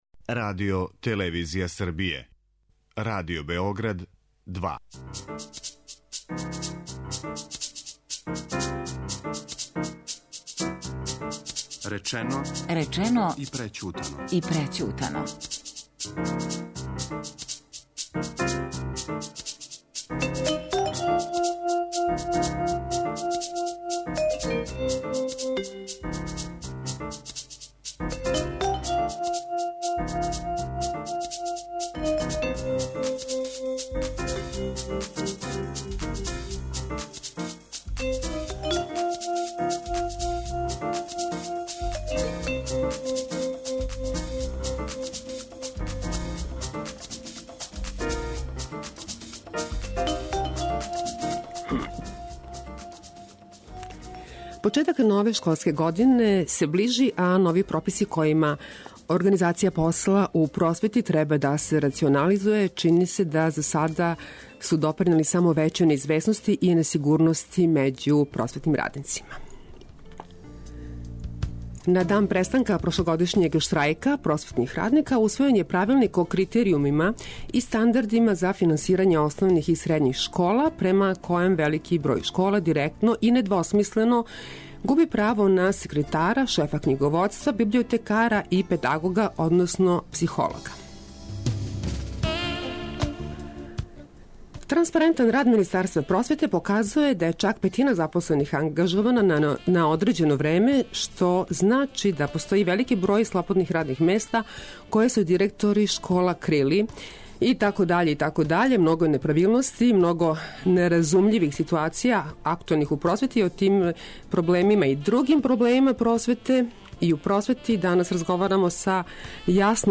О тим и другим проблемима у просвети данас разговарамо